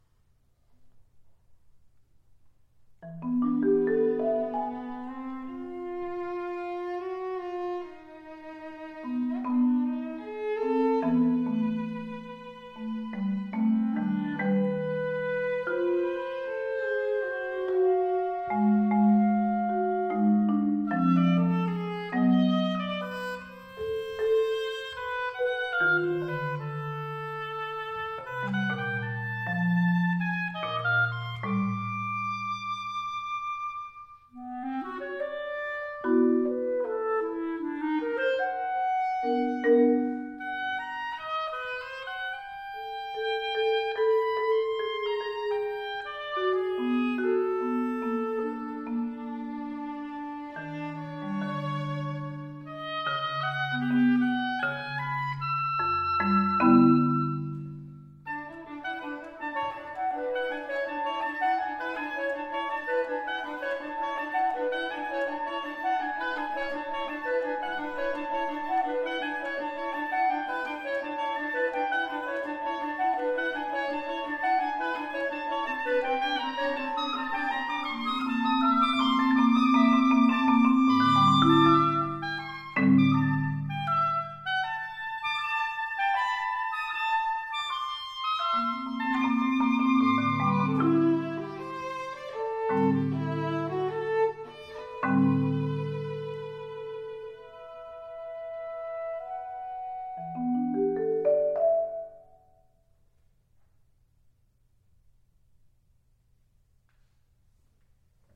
2016.   oboe, clarinet, viola, marimba.
Premiere performance February 6, 2018, at Texas State University Performing Arts Center: